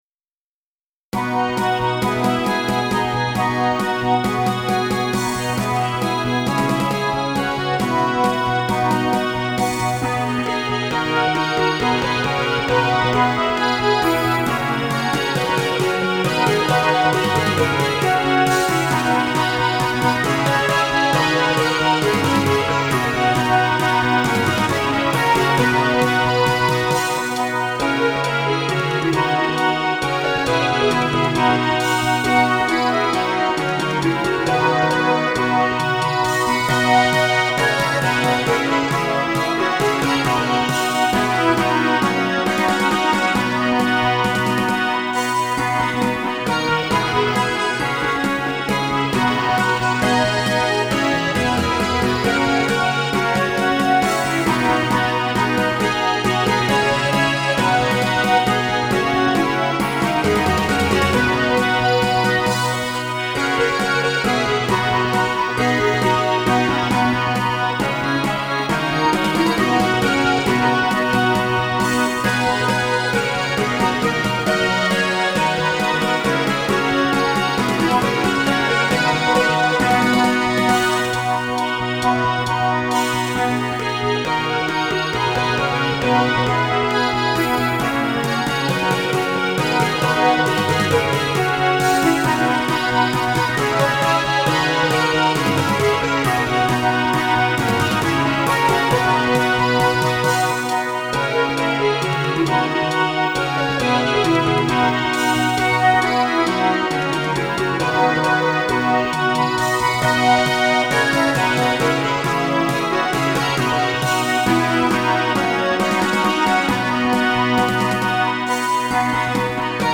ロ長調
この曲は４分の５拍子です。